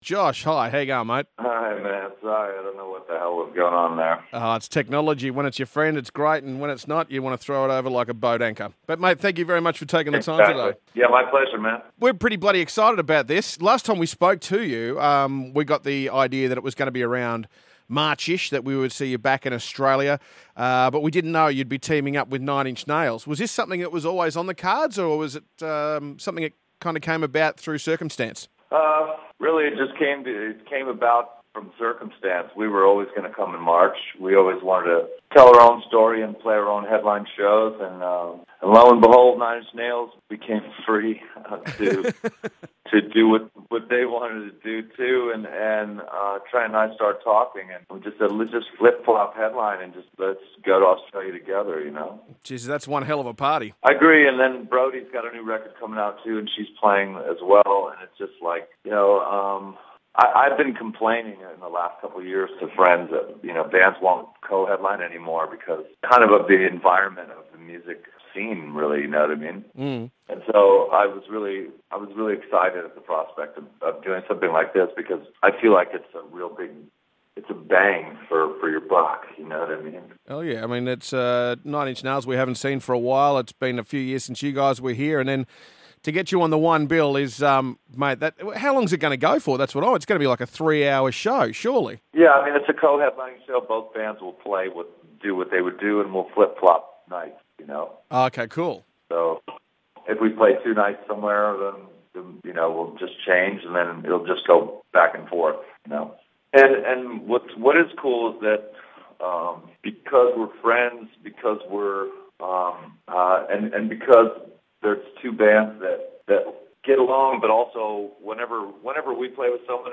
Interview with Josh Homme (October 2013)